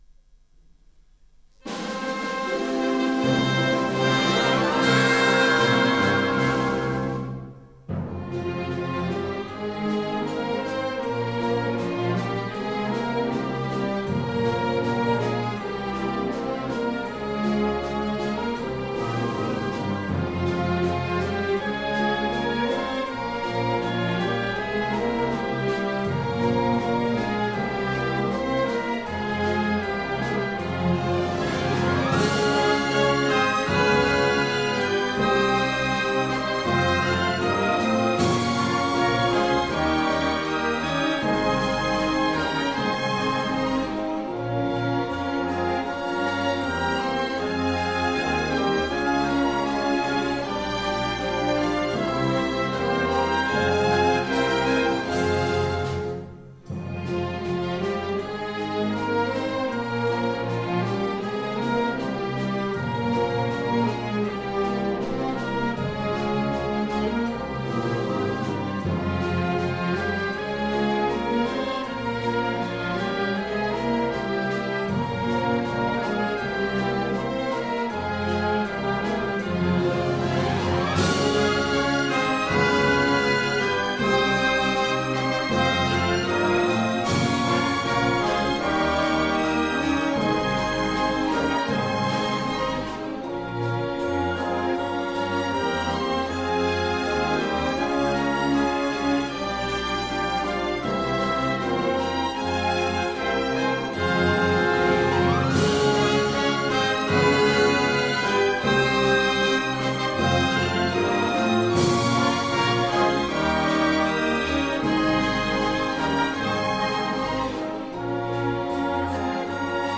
Инструментальная версия гимна Республики Хакасия